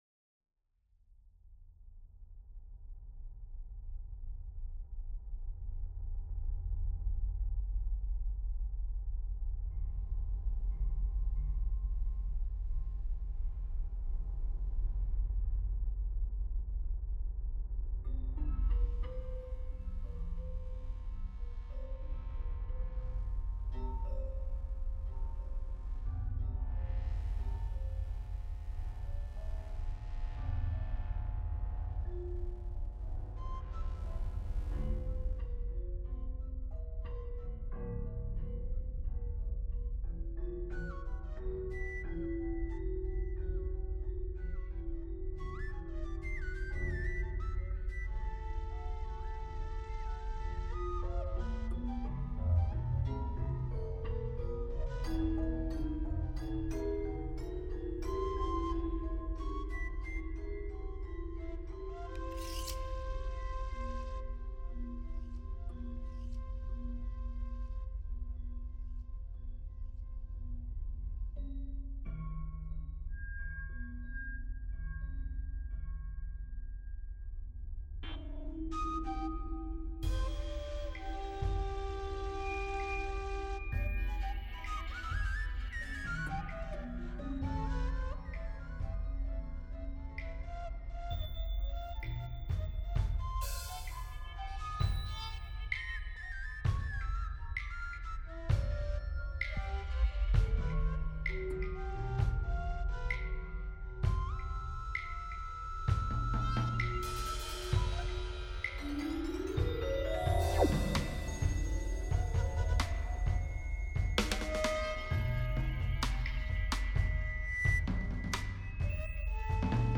Composition musicale pour sorcière, synthétiseurs, pianos, contrebasse, batterie, Ney (flûte), voix, cordes, sons et quelques effets choisis.